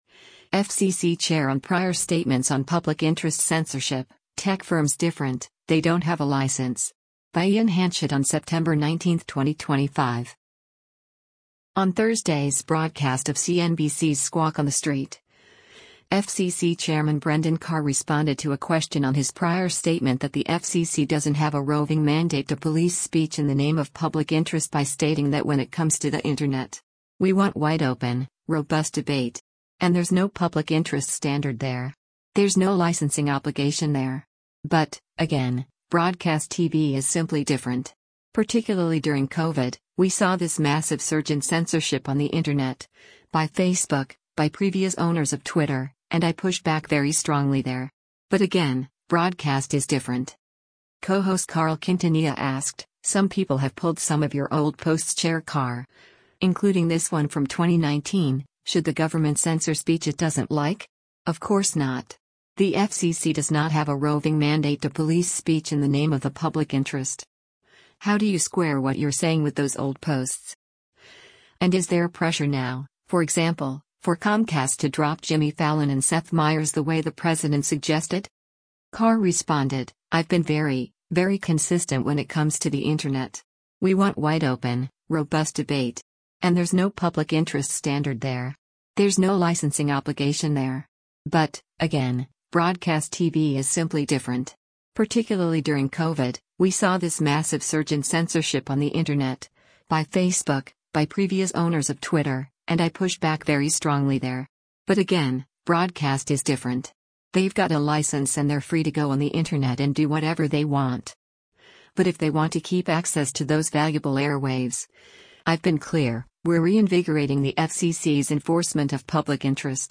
On Thursday’s broadcast of CNBC’s “Squawk on the Street,” FCC Chairman Brendan Carr responded to a question on his prior statement that the FCC doesn’t have “a roving mandate to police speech” in the name of public interest by stating that “when it comes to the Internet. We want wide open, robust debate. And there’s no public interest standard there. There’s no licensing obligation there. But, again, broadcast TV is simply different. Particularly during COVID, we saw this massive surge in censorship on the Internet, by Facebook, by previous owners of Twitter, and I pushed back very strongly there. But again, broadcast is different.”